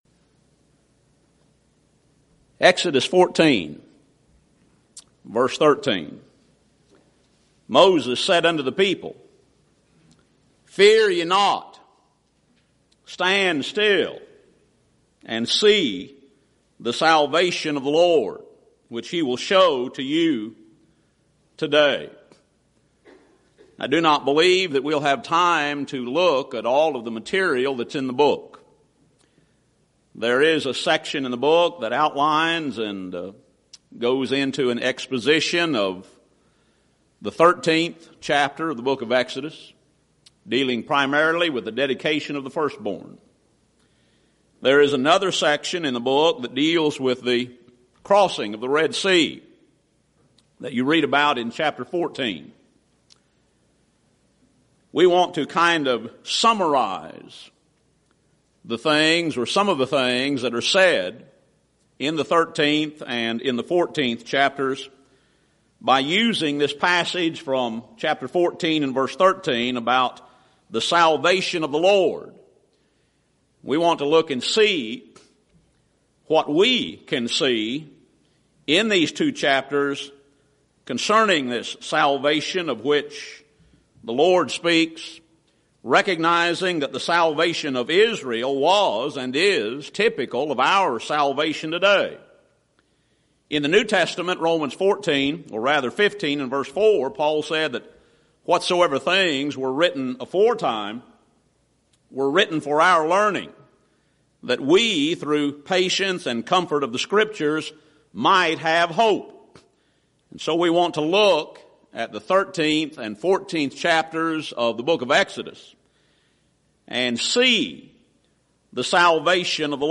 Event: 2nd Annual Schertz Lectures Theme/Title: Studies In Exodus
lecture